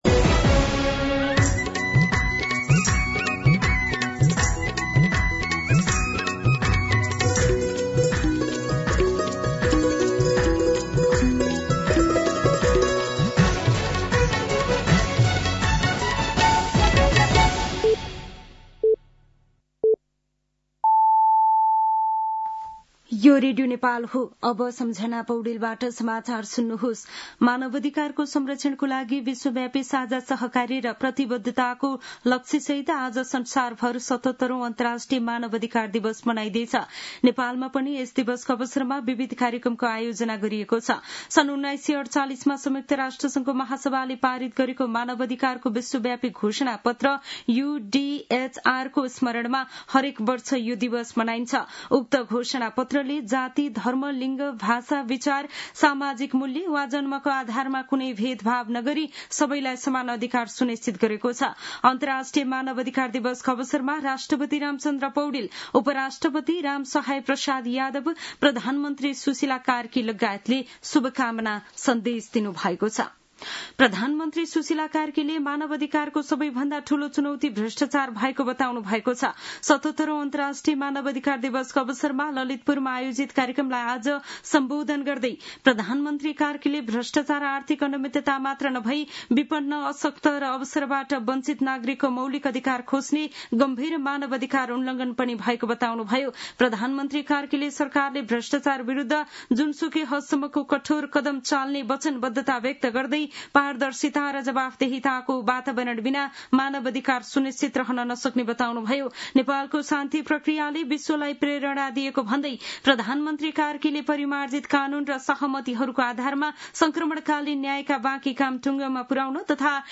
दिउँसो १ बजेको नेपाली समाचार : २४ मंसिर , २०८२
1pm-News-08-24.mp3